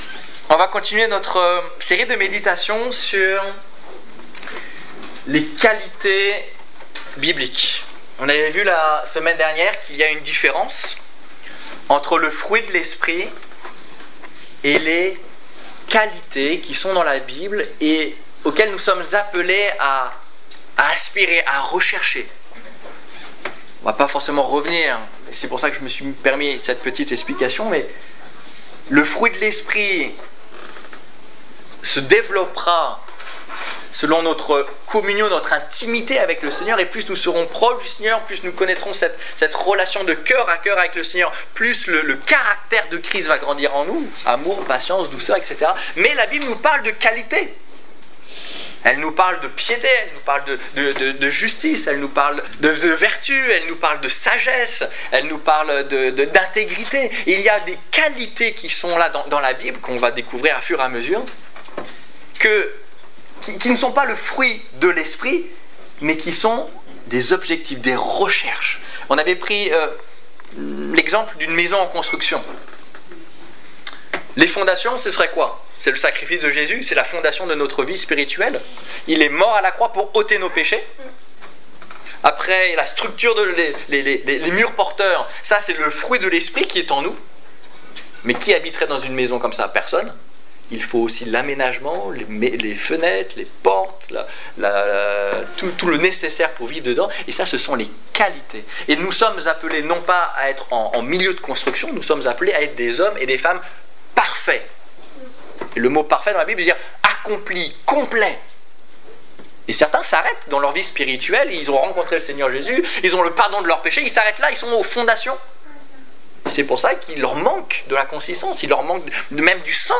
Quelques qualités bibliques - La sagesse (2) Détails Prédications - liste complète Culte du 13 septembre 2015 Ecoutez l'enregistrement de ce message à l'aide du lecteur